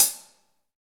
HH HH 320AL.wav